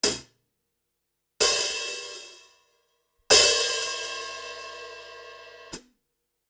Próbki dzwiękowe Audio Technica AE-3000
Audio Technica AE3000 mikrofon - HiHats